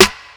Almost Snare.wav